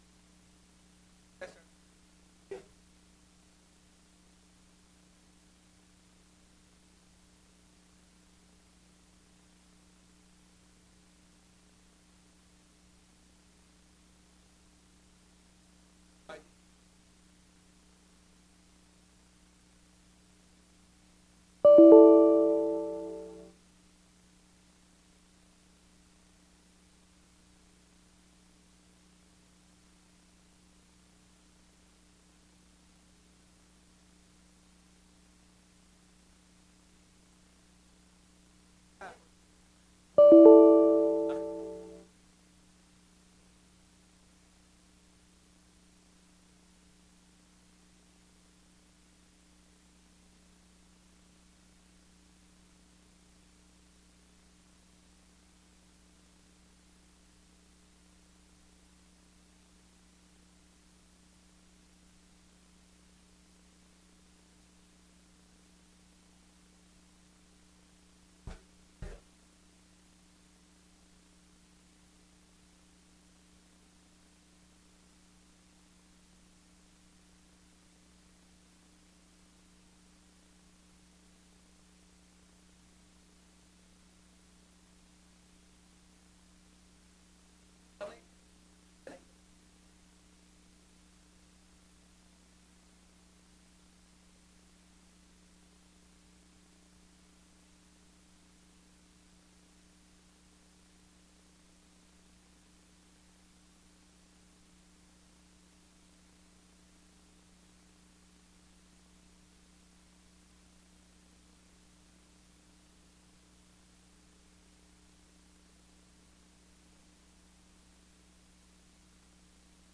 2ª Sessão Extraordinária de 2021